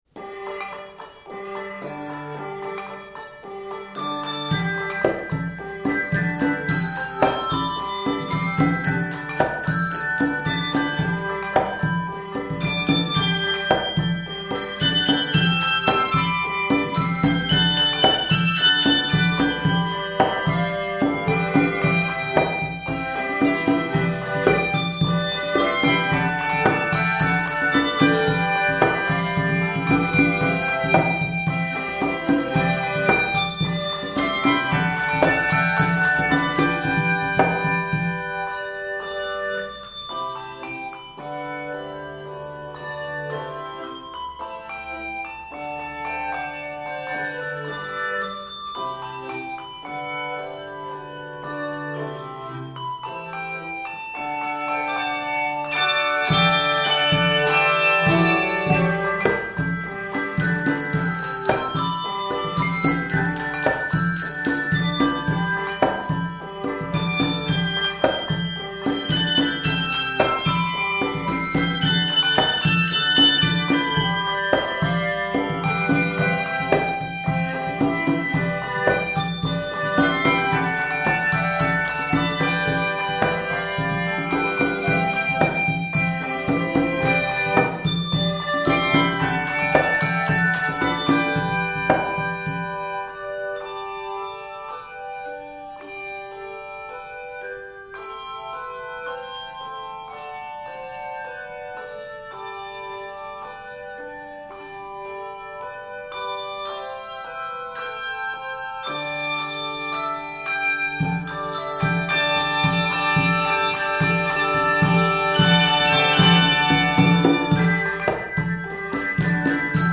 Various Octaves: 3-5 Level